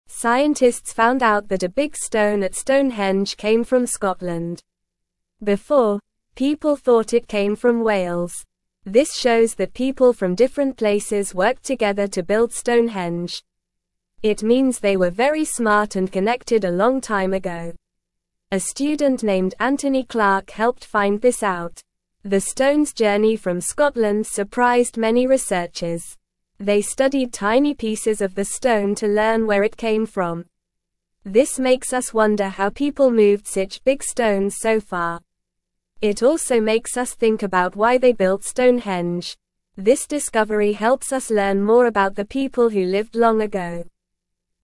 Normal
English-Newsroom-Beginner-NORMAL-Reading-Big-Stone-at-Stonehenge-Came-from-Scotland-Not-Wales.mp3